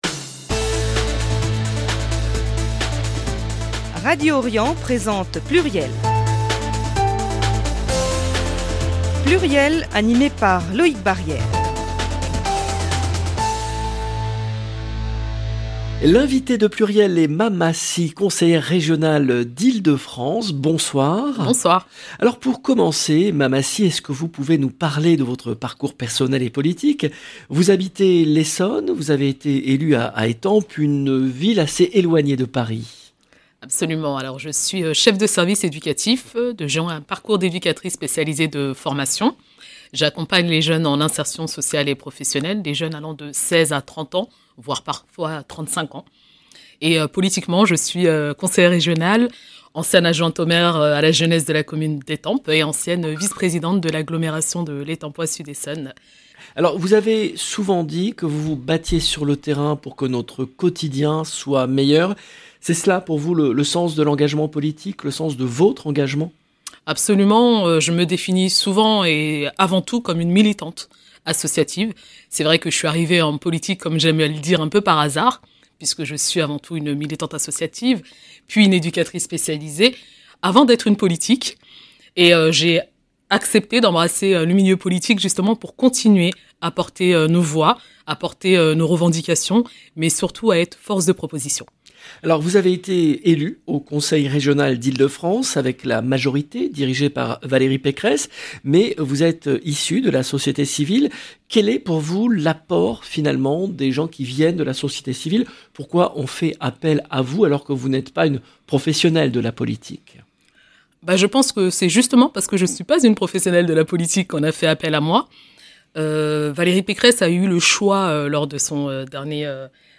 Mama Sy, conseillère régionale d’Ile-de-France